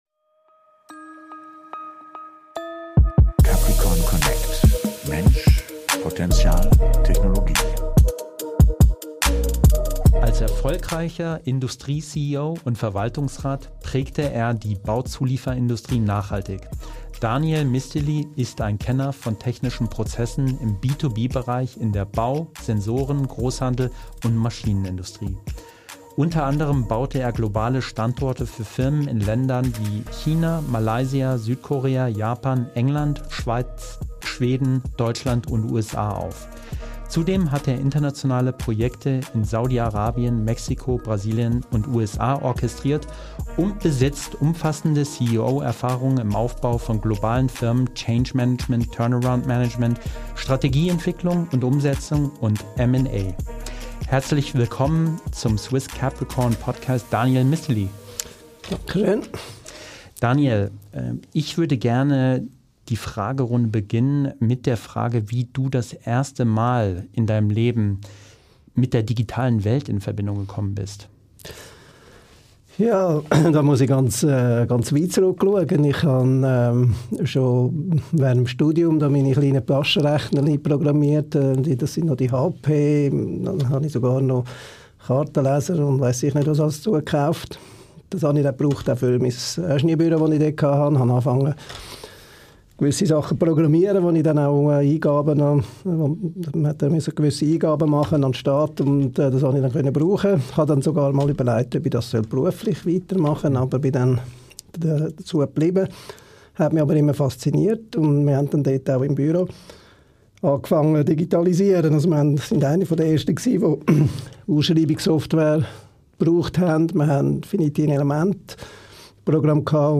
#22 - Interview